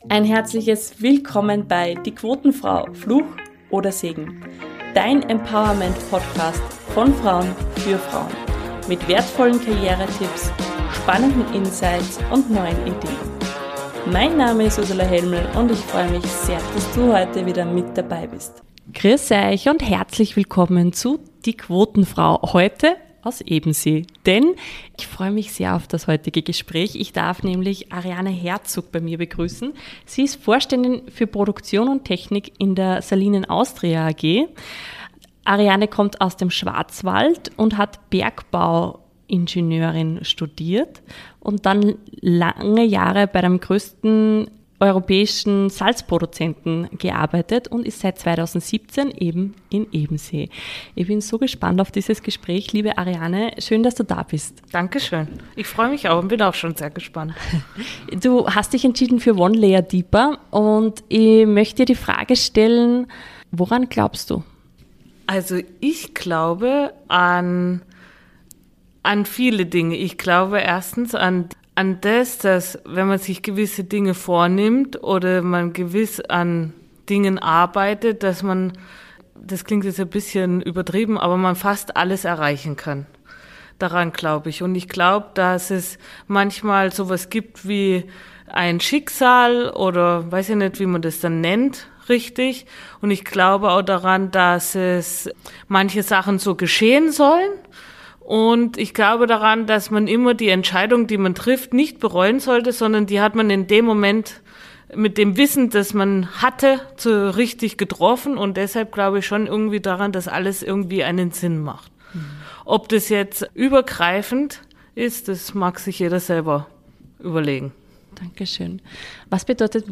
Die QuotenFrau im Gespräch